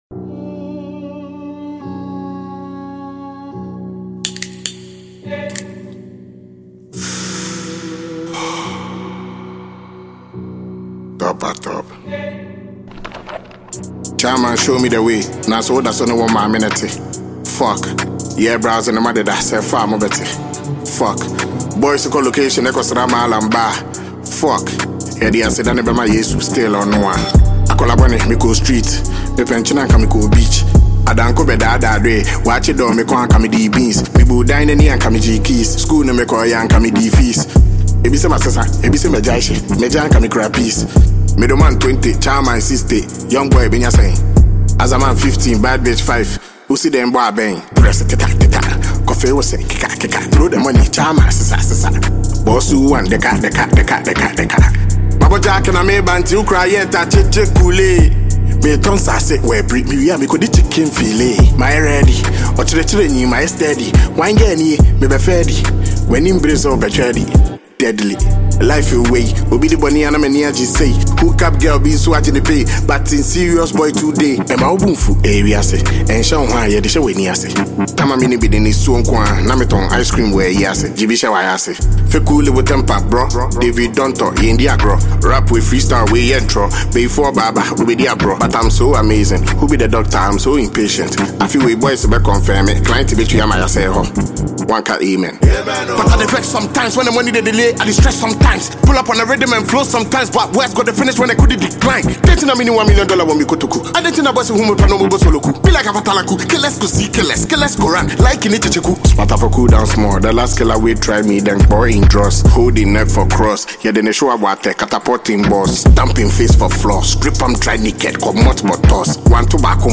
Ghanaian rap star
delivering unfiltered bars over a hard-hitting beat.